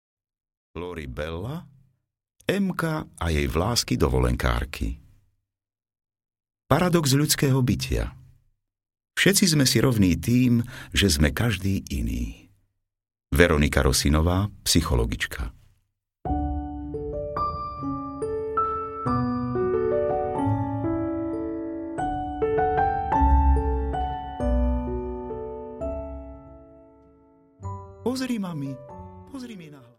Emka a jej vlásky dovolenkárky audiokniha
Ukázka z knihy